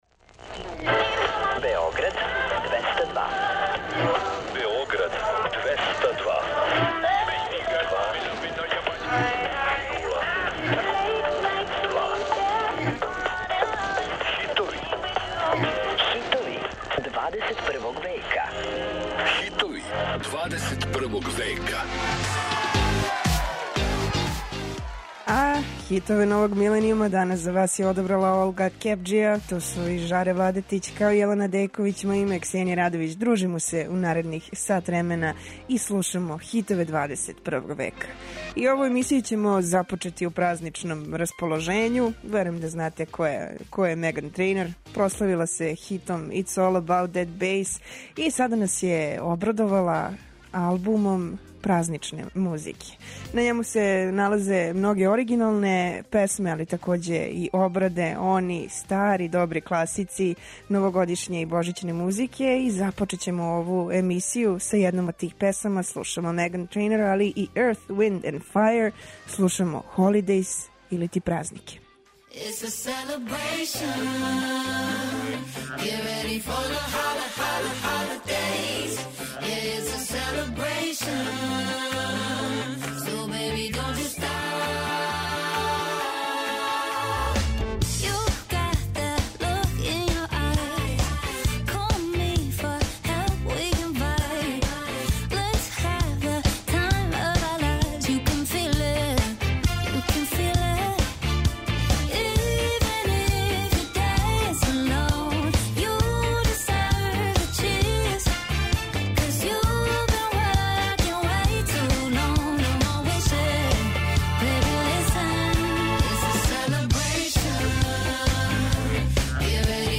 Слушамо хитове новог миленијума, које освајају топ листе и радијске станице широм планете.